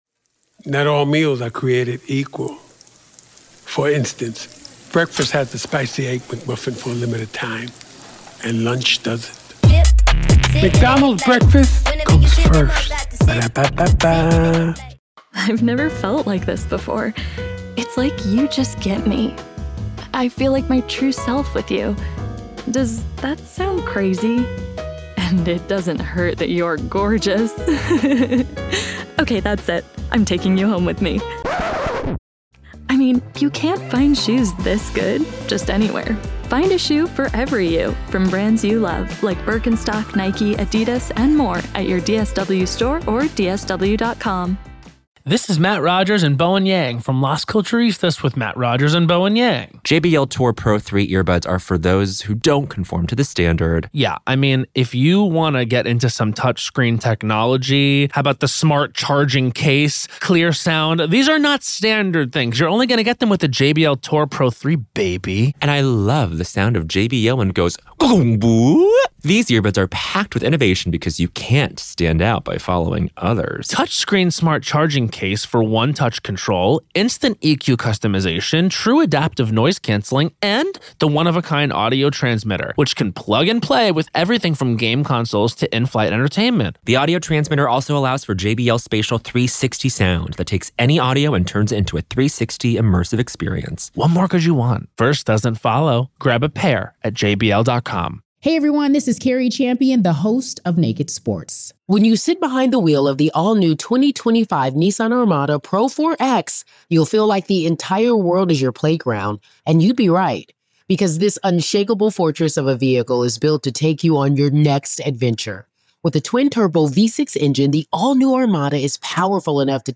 Dr. Katherine Ramsland Interview: Behind The Mind Of BTK Part 2